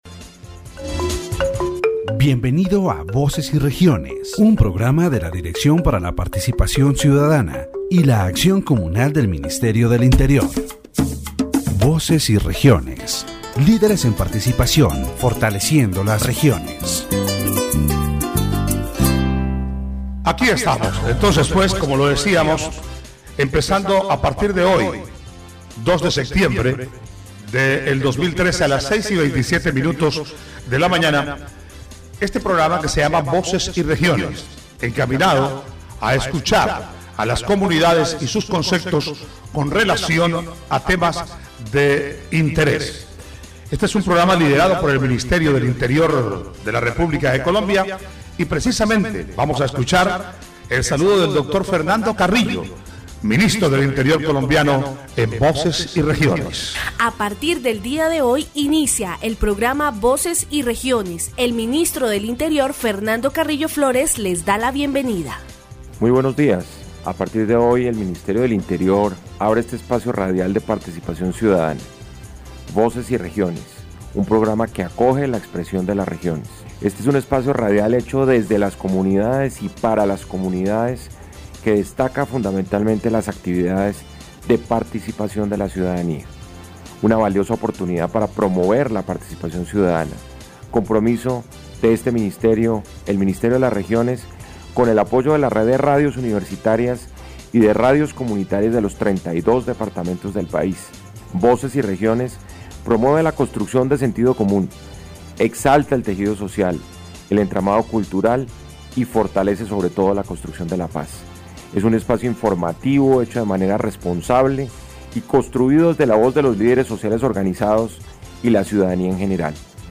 The radio program "Voices and Regions," led by Colombia's Ministry of the Interior, aims to strengthen citizen participation by providing a platform for communities to express their ideas and needs. In this inaugural broadcast, Interior Minister Fernando Carrillo Flores introduces the program's objective: to promote community leadership and peacebuilding through active civic engagement. The importance of Community Action Boards and other organizations as fundamental mechanisms for citizen involvement in decision-making is emphasized.